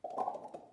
大规模录音根特" 011年手指弹出的混乱T4
描述：声音是在比利时根特的大规模人民录音处录制的。
一切都是由4个麦克风记录，并直接混合成立体声进行录音。每个人都用他们的手指在嘴里发出啪啪的声音。混乱的，没有时间或平移。